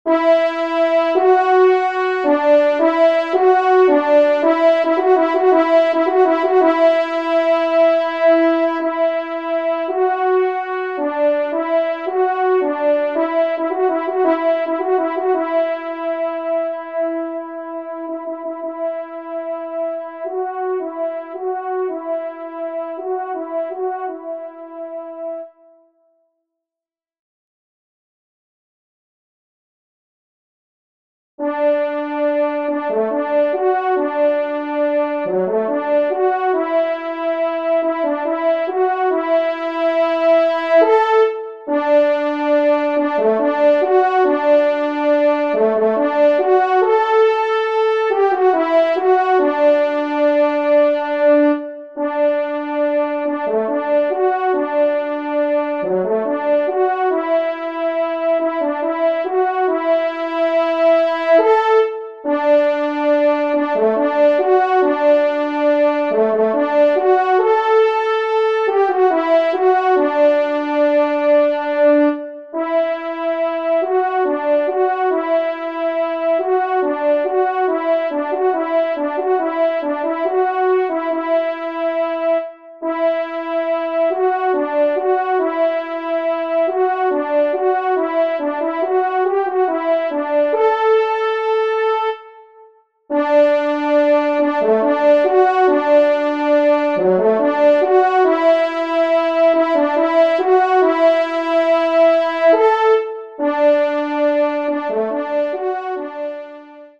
1e Trompe